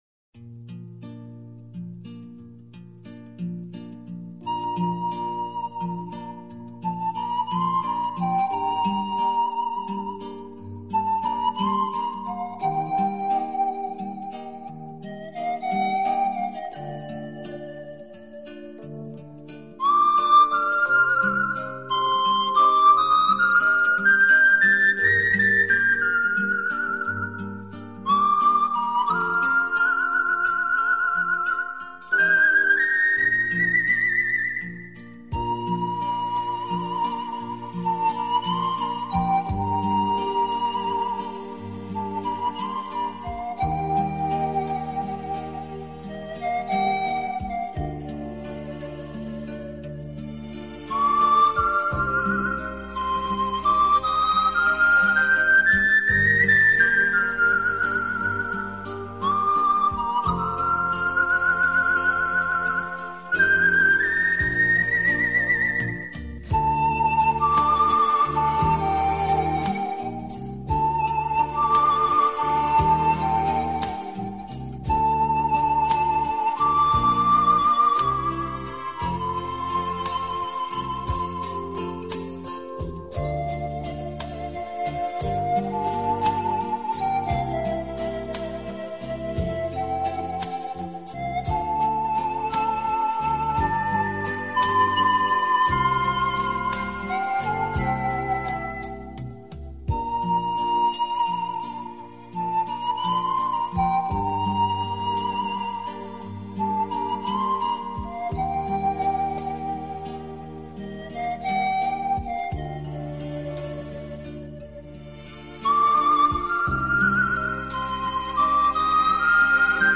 音乐类型：Classical Guitar
绎的排箫音乐在悠扬中隐藏着哀伤，悠扬清越，充满诗意，听者无不动容。
柔情似水般的吉他声以及充满罗曼蒂